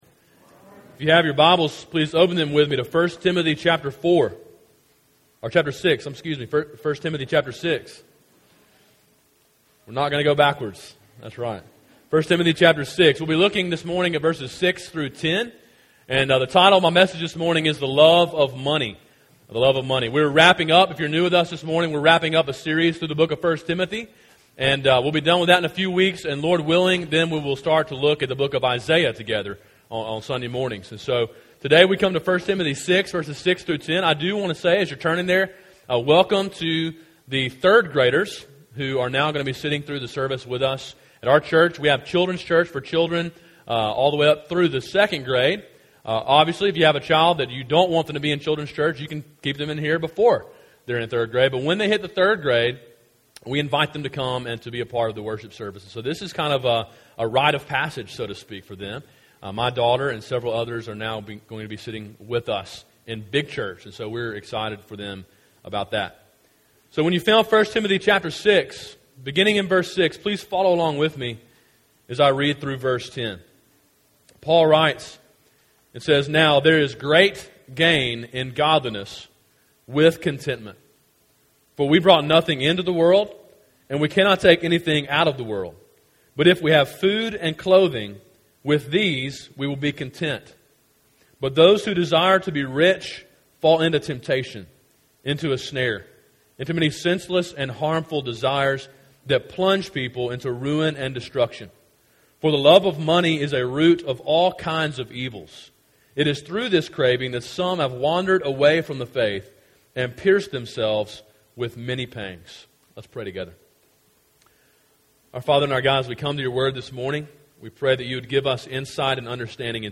Sermon: “The Love of Money” (1 Timothy 6:6-10)
A sermon in a series on the book of 1 Timothy.